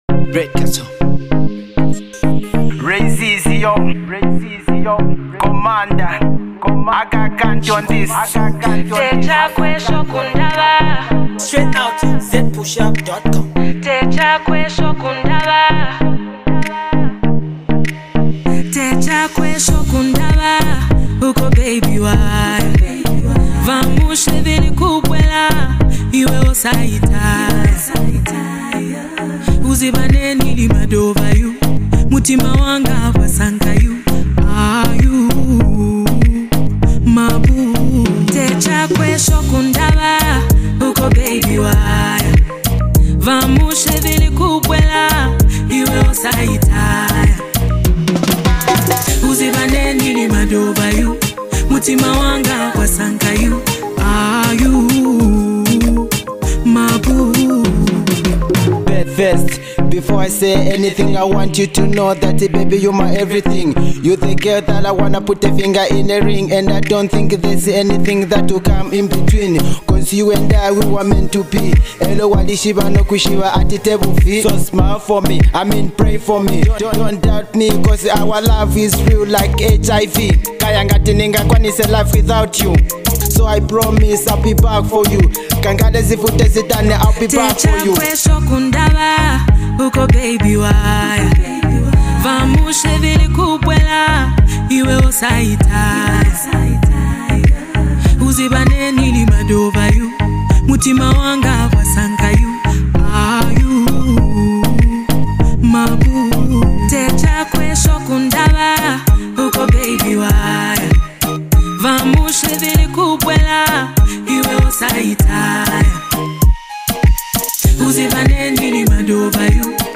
well mastered track